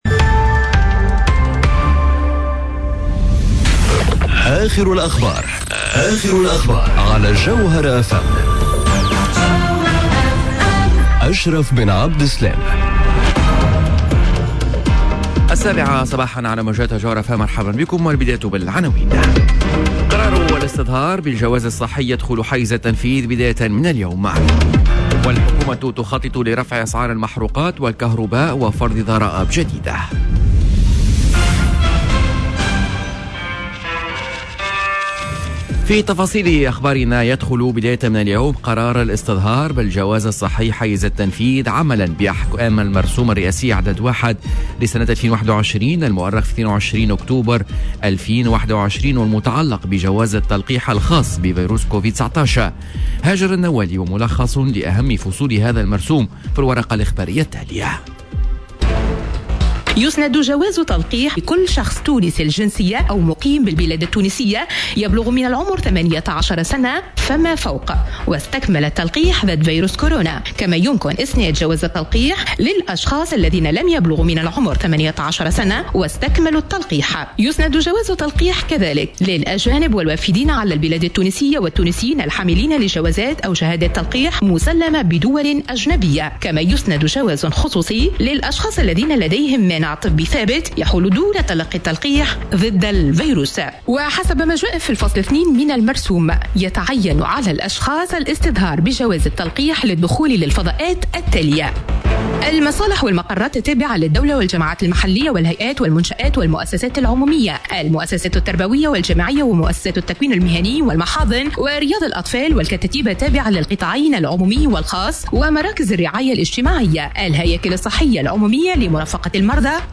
نشرة أخبار السابعة صباحا ليوم الإربعاء 22 ديسمبر 2021